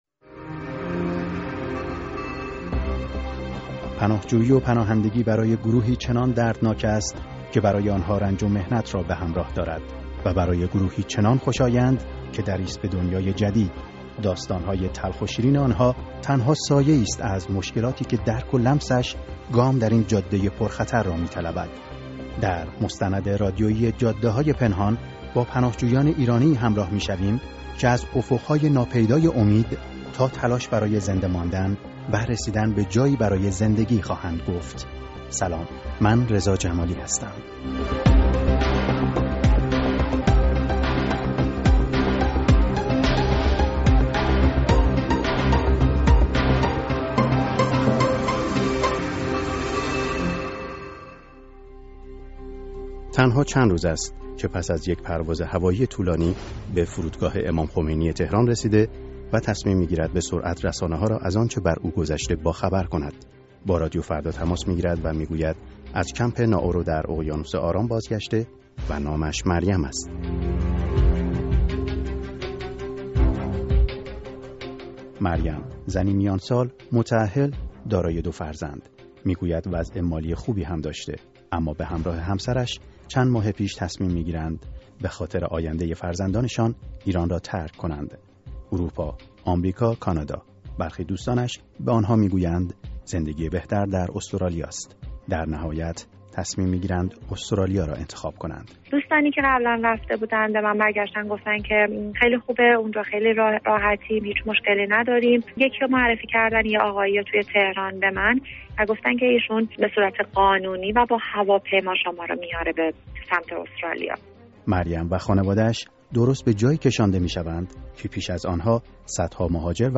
در مستند رادیویی «جاده‌های پنهان» با پناهجویان ایرانی همراه می‌شویم که از افق‌های ناپیدای امید تا تلاش برای زنده ماندن و رسیدن به جایی برای زندگی خواهند گفت.